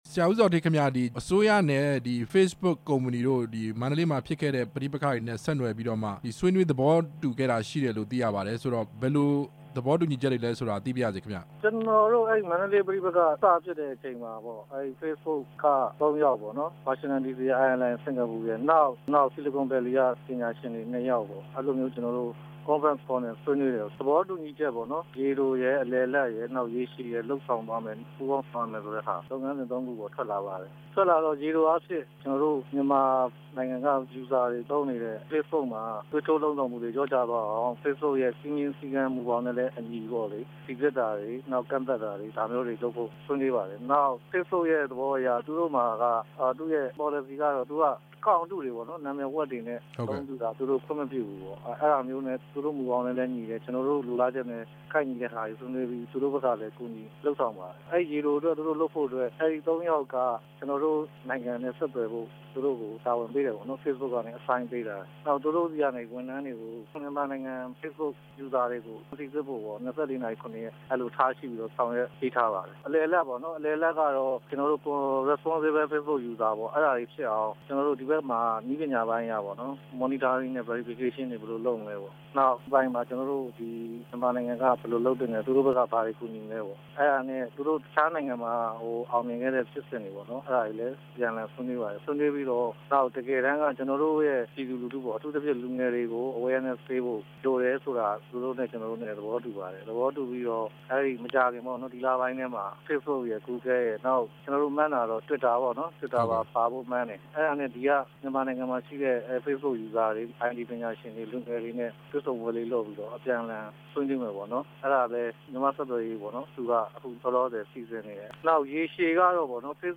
ဦးဇော်ဌေး နဲ့ ဆက်သွယ်မေးမြန်းချက်